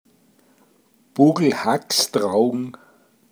Details zum Wort: buglkragstraogn - Pinzgauer Mundart Lexikon